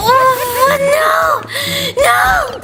Worms speechbanks
uh-oh.wav